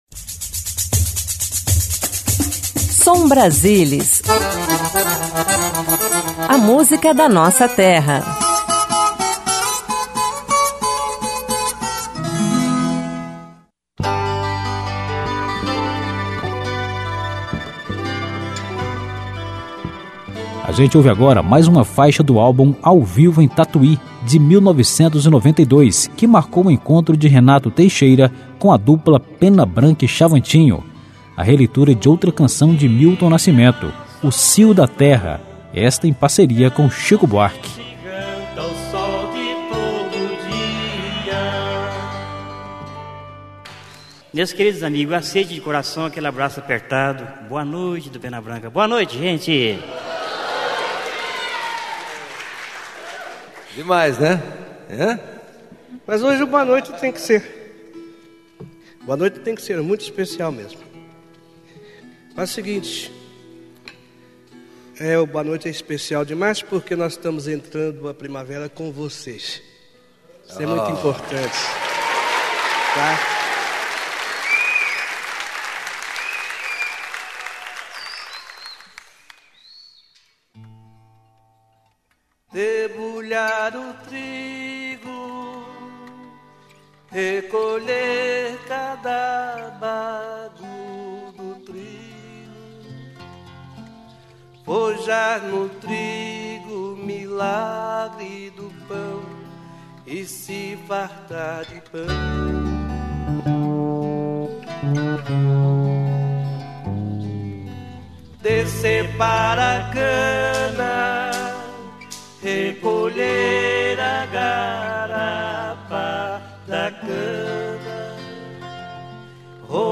Moda de Viola
Música sertaneja Viola Caipira Música folclórica